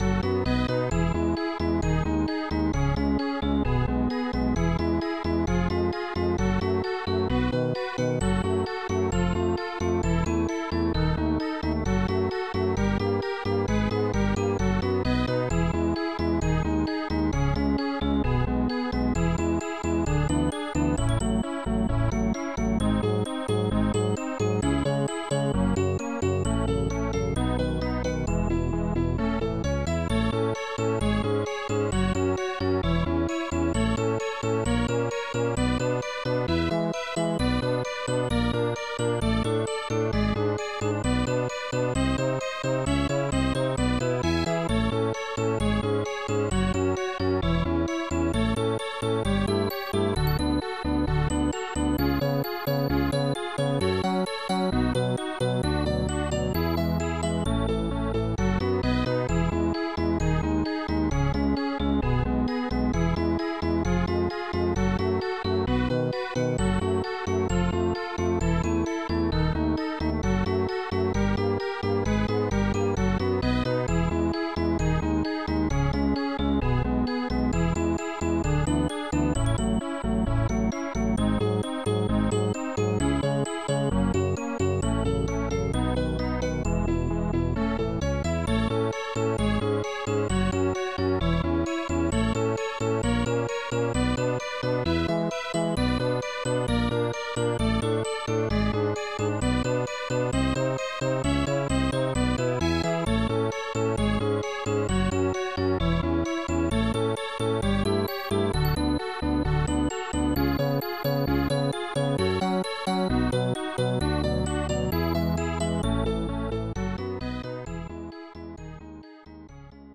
This file is an audio rip from a(n) SNES game.